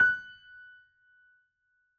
piano_078.wav